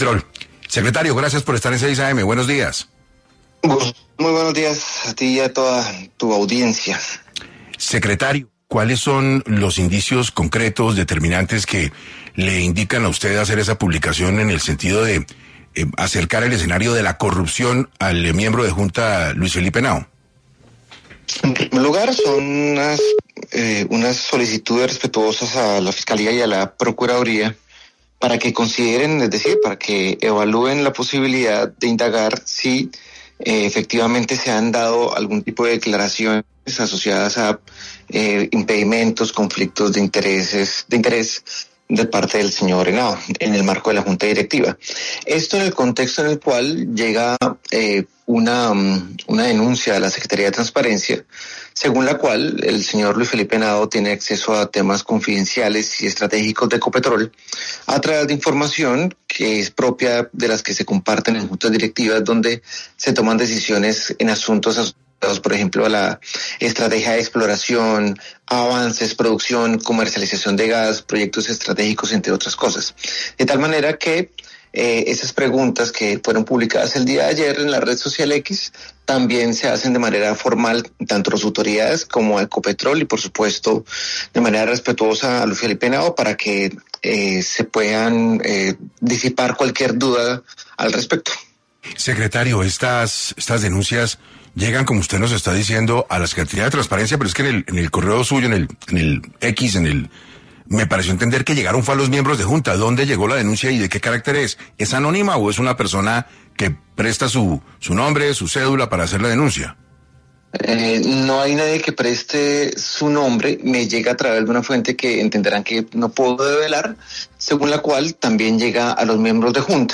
El secretario de Transparencia de la Presidencia habló en 6AM de Caracol Radio sobre la solicitud que hizo a la Procuraduría y la Fiscalía pidiendo la verificación de información de la junta directiva de Ecopetrol.